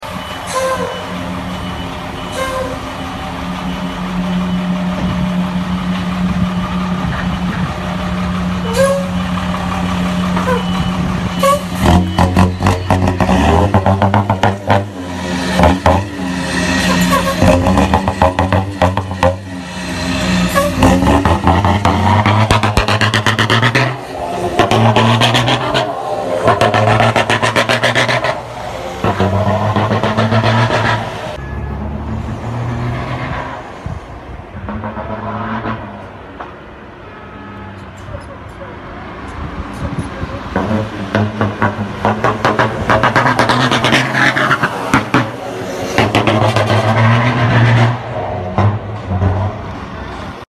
Ankara’ya vanalı egzoz hayırlı olsun🧿 sound effects free download